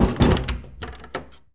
Door Close